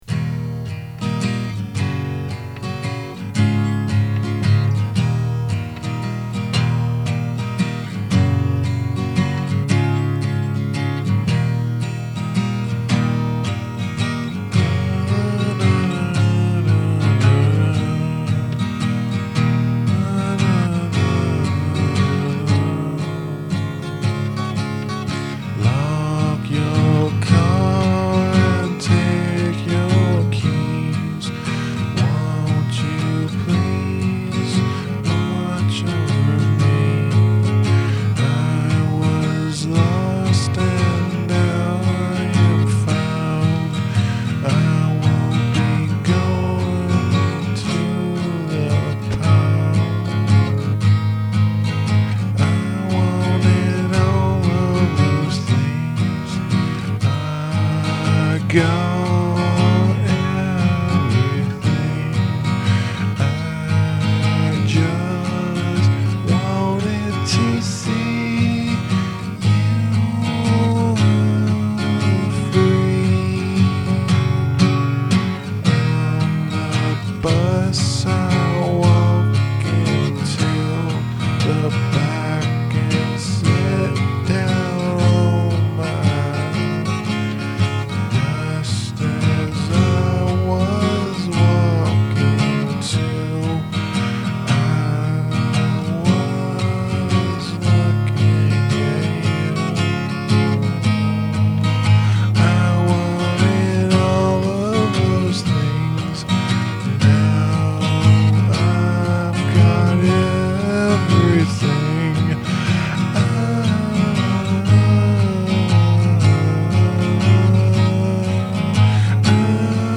Once in a while, I pick up my guitar, sit in front of a microphone and press “record” without knowing what is going to happen. Occasionally, the song that results from this turns out surprisingly good.